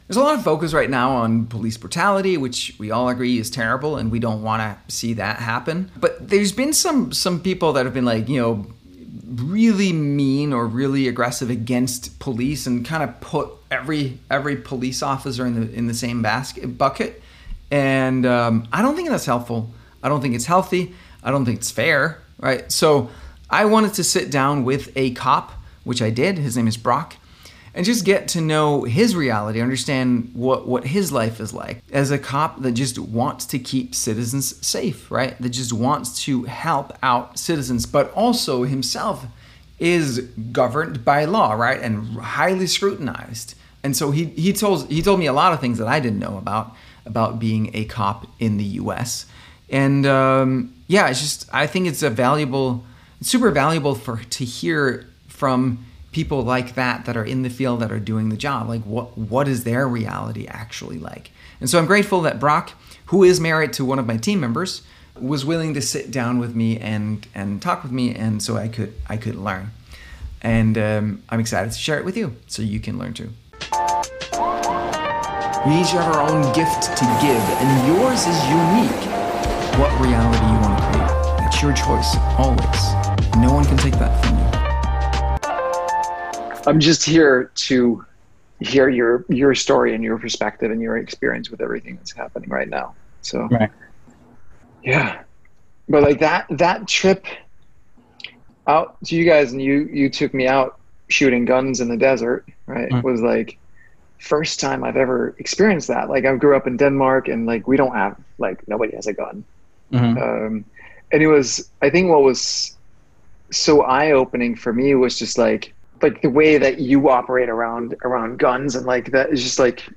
What It’s Like To Be a Cop These Days Interview with Arizona Police Officer